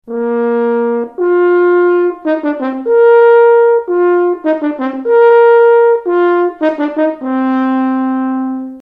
French horn, and
hornriff.mp3